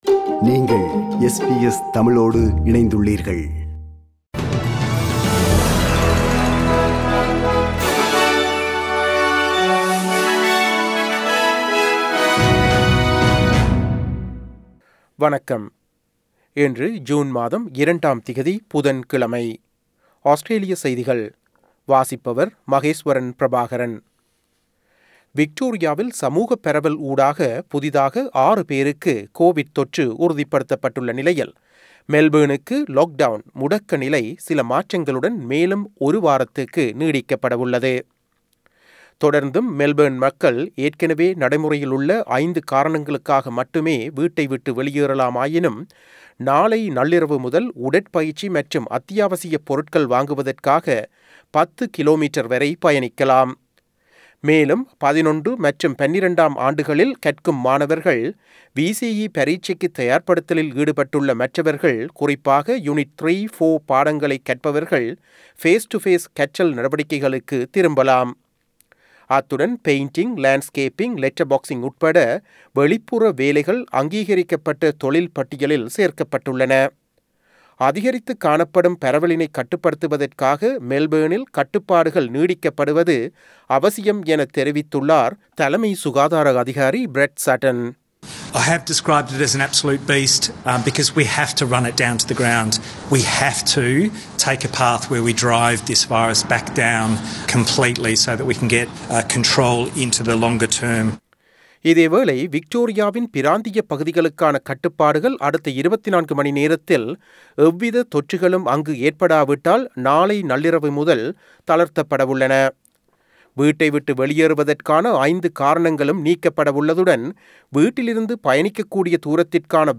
Australian news bulletin for Wednesday 02 June 2021.